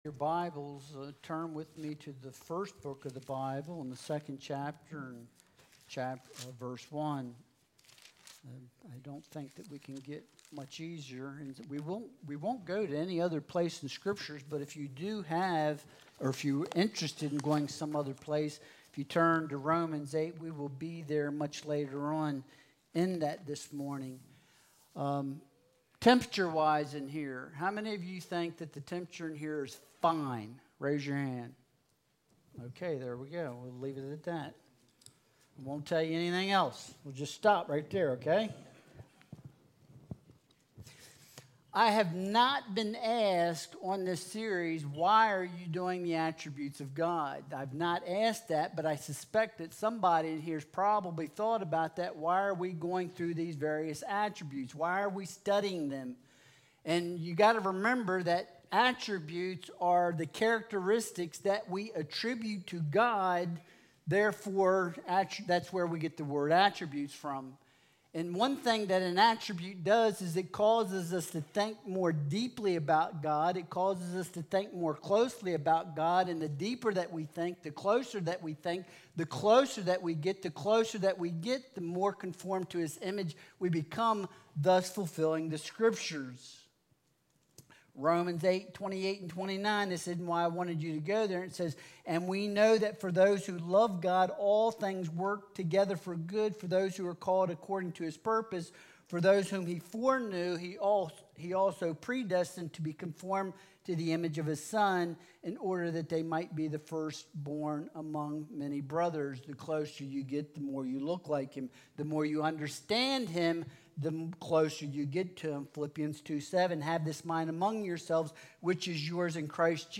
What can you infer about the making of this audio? Genesis 2.1 Service Type: Sunday Worship Service Attribute